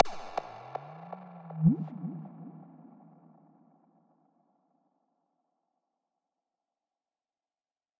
描述：带有延迟效果的小鼓敲击声，120bpm
声道立体声